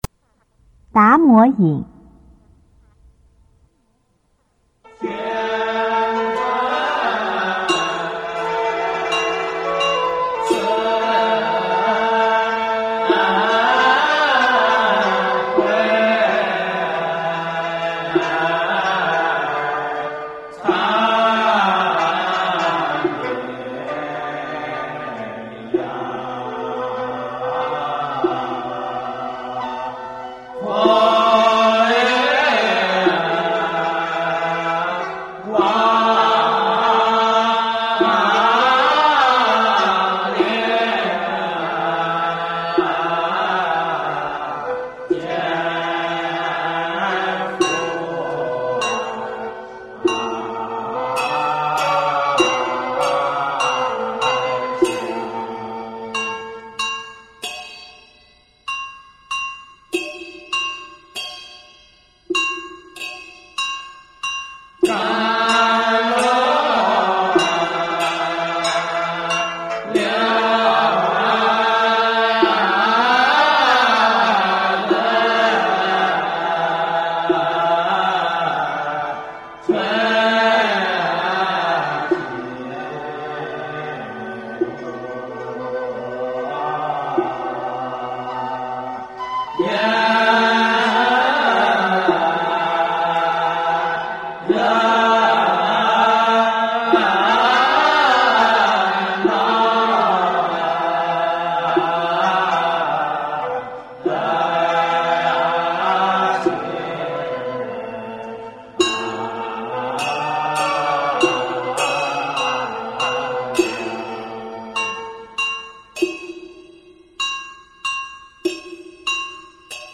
用途：达摩引用于铁罐施食科仪中，藉由圣真的慈惠，超度亡灵早日解脱。